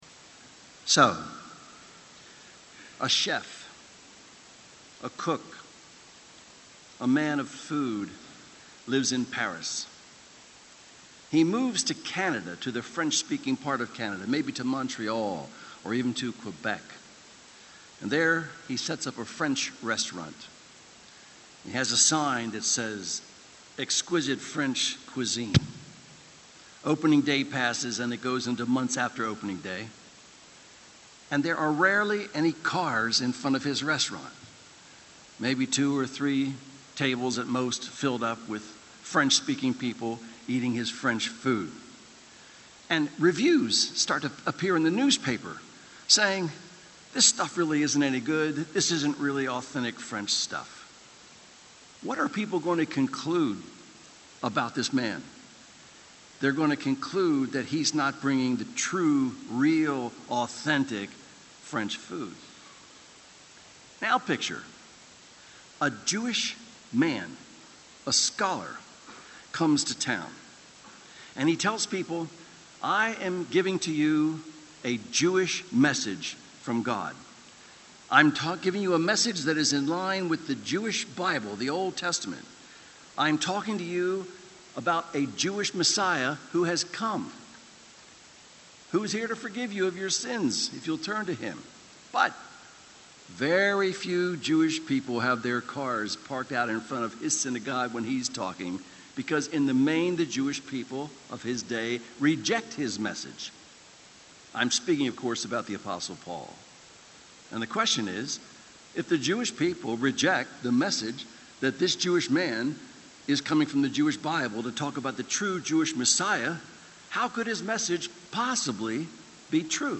Audio Sermons — Brick Lane Community Church
MP3 audio sermons from Brick Lane Community Church in Elverson, Pennsylvania.